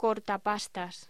Locución: Cortapastas
voz
Sonidos: Hostelería